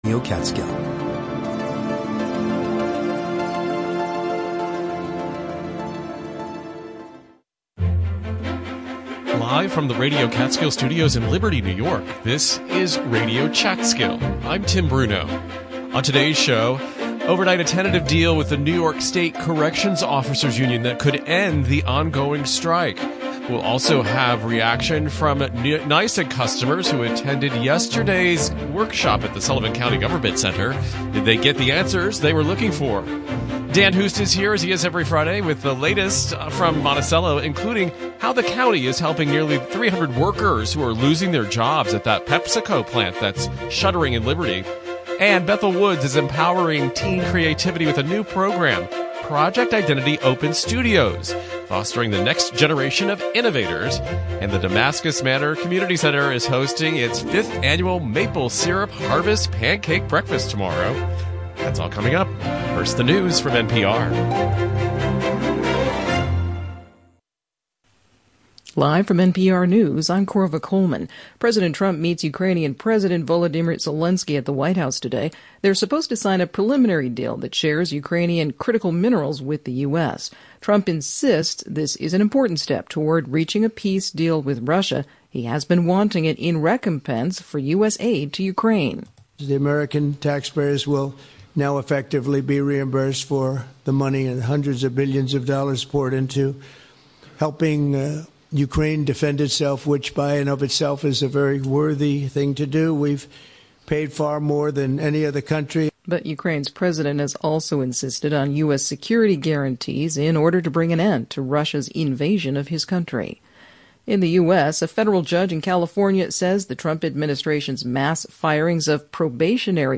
Live, local conversations focused on arts, history, and current news.